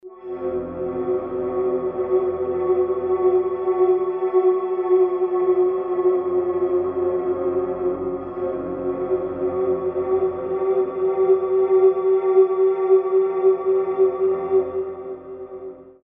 Tag: 120 bpm Ambient Loops Fx Loops 2.69 MB wav Key : Unknown